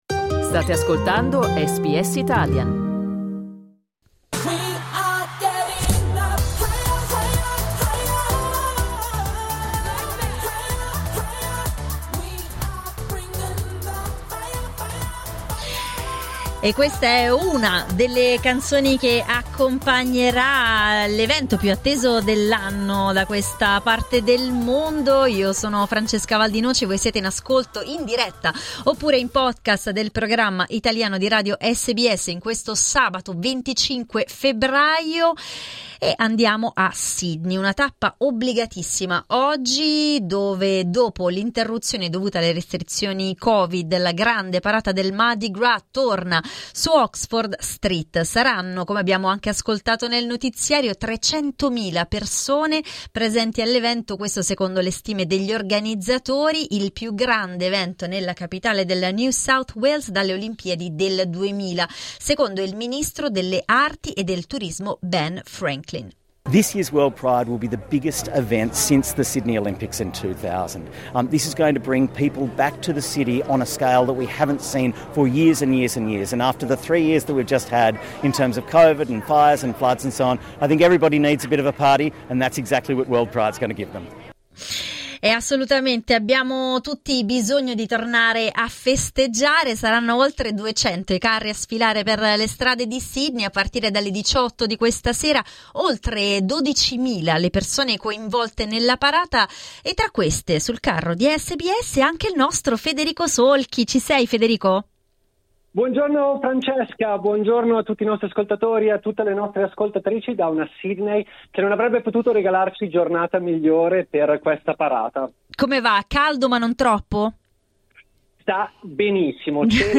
Per ascoltare l'intervento in diretta da Sydney, clicca sul player in alto.